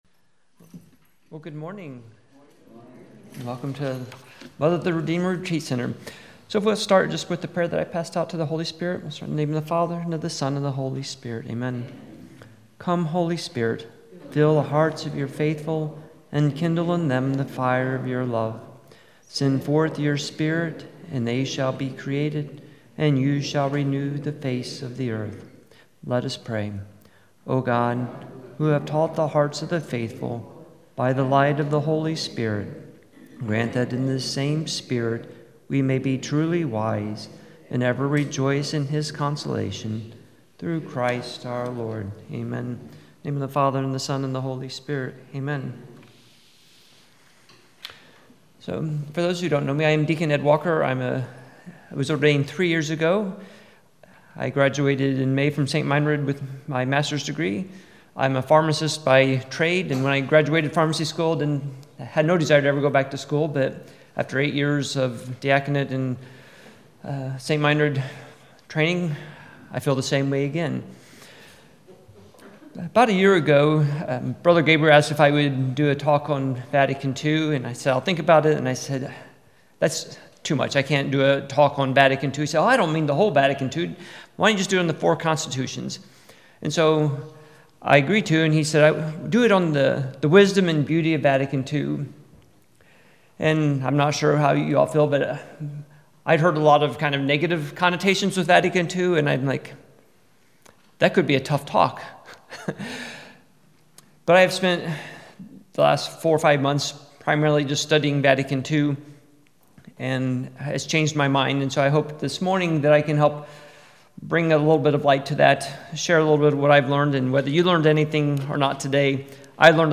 Introduction to Vatican II – Retreat